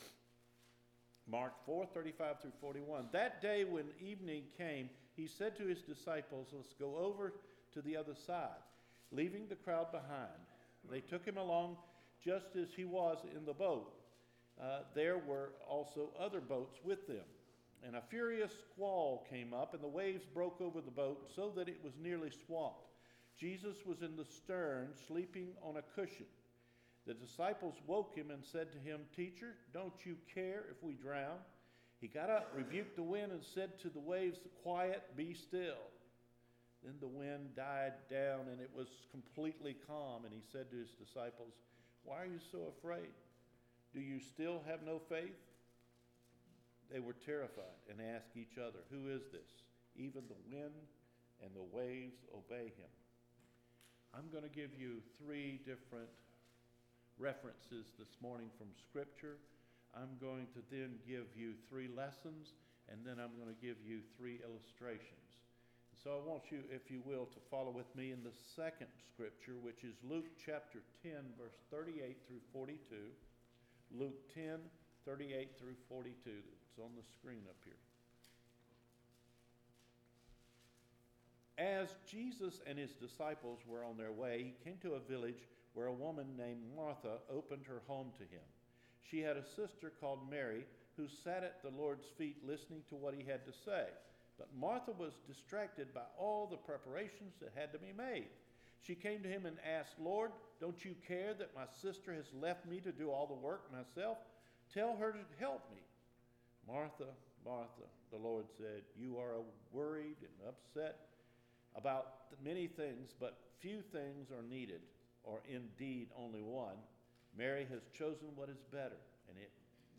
GOD IS GOOD ALL THE TIME – MARCH 3 SERMON